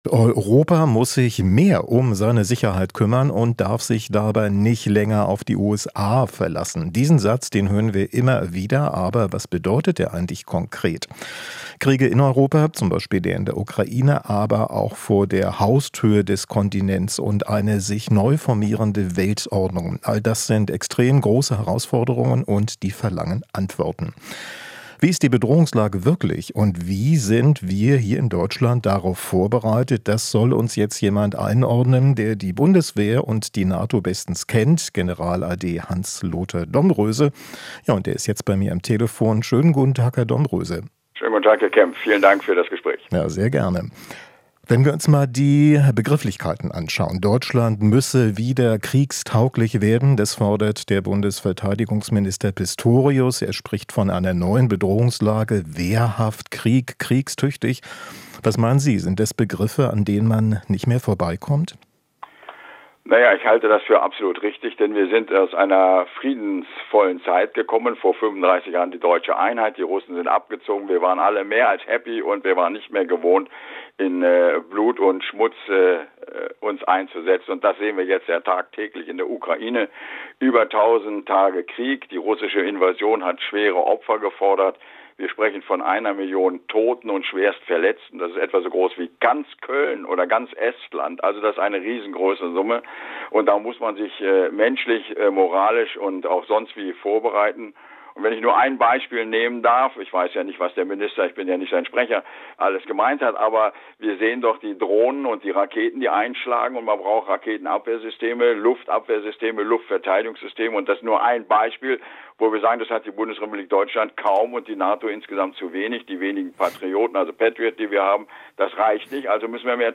Interview - Domröse: In Verteidigung investieren, um Krieg zu verhindern